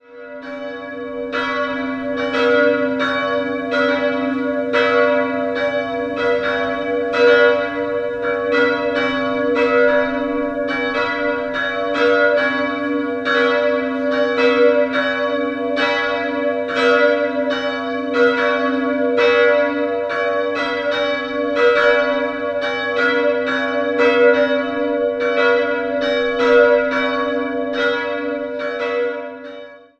2-stimmiges Geläute: b'(+)-des'' Die größere Glocke aus Eisenhartguss wurde 1922 von der Firma Schilling&Lattermann gegossen, die kleinere stammt aus dem Jahr 1958 und entstand bei Georg Hofweber in Regensburg.